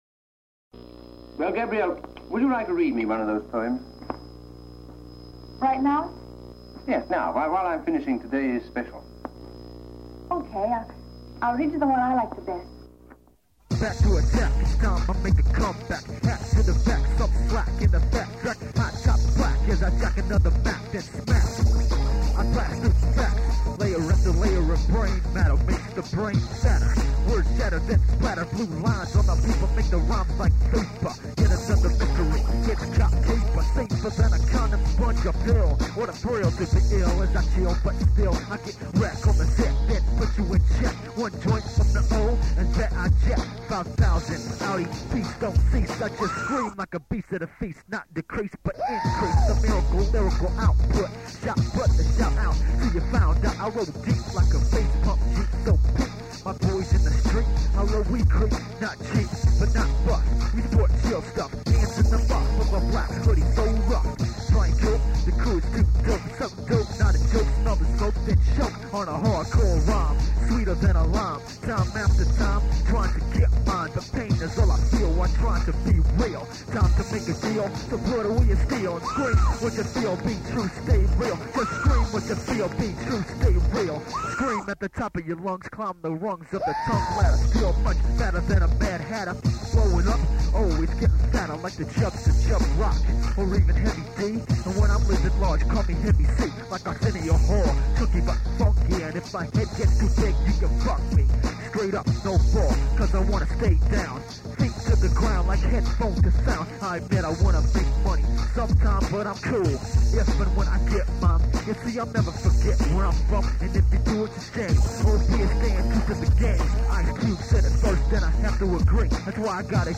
This funky-ass song